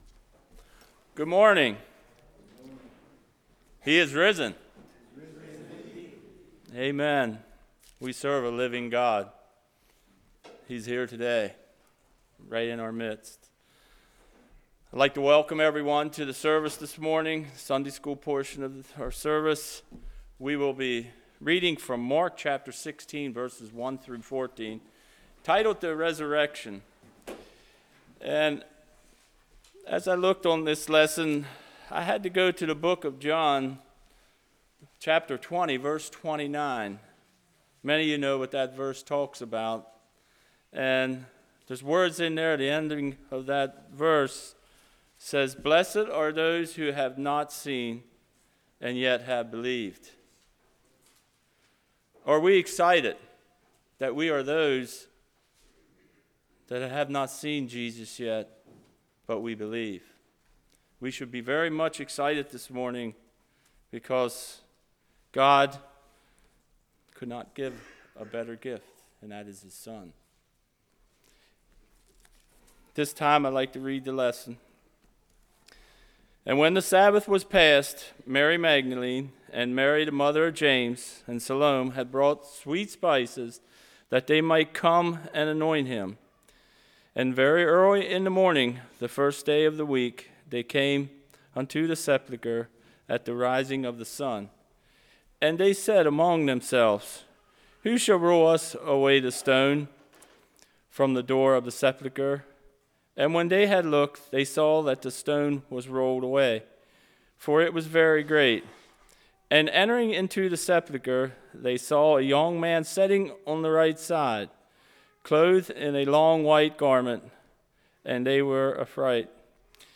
Passage: Mark 16:1-14 Service Type: Sunday School